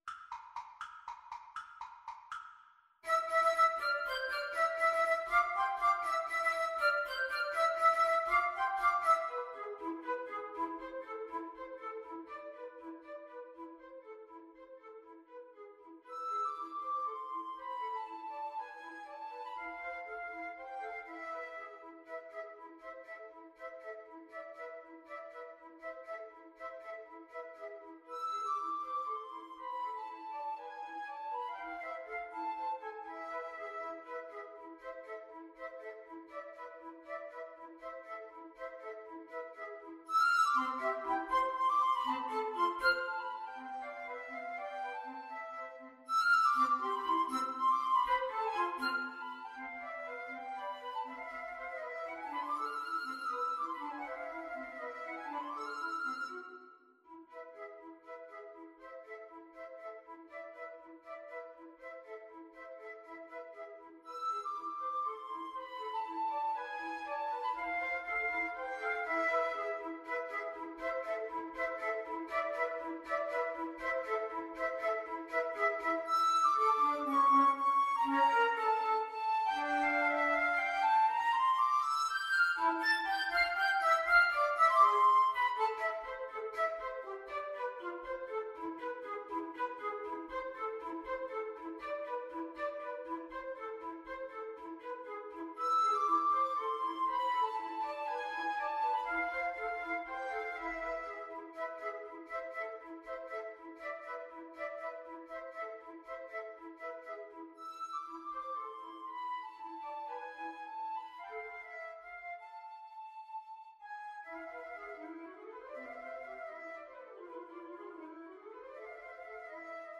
Allegro vivo (.=80) (View more music marked Allegro)